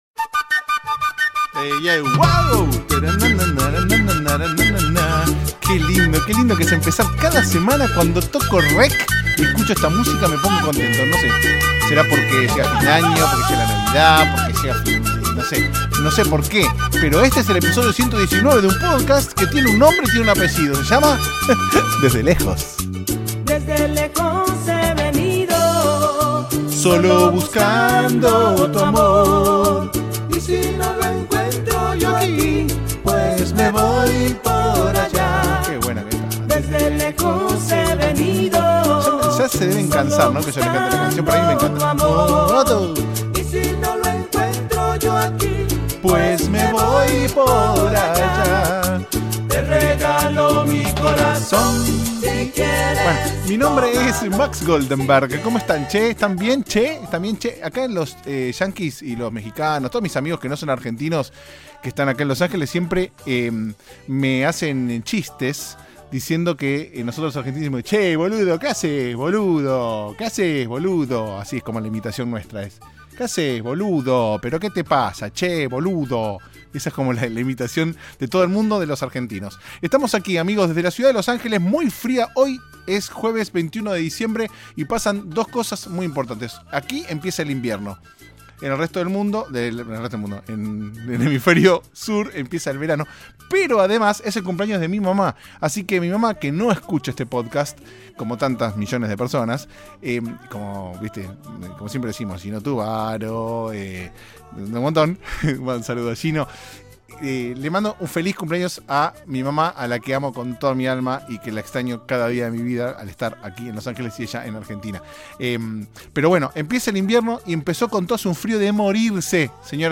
También escuchamos canciones navideñas mientras pensaba en cómo se organizan las orgías.